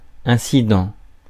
Ääntäminen
US
IPA : /ˈɪn.sɪ.dənt/